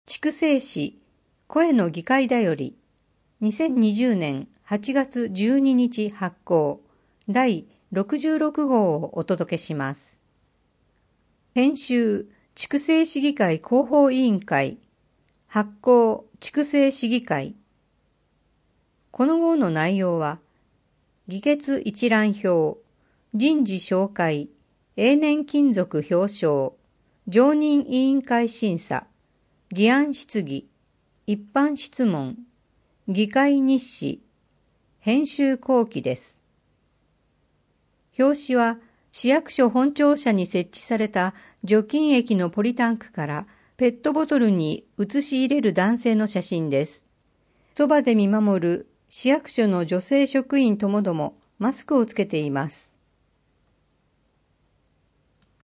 声の議会だより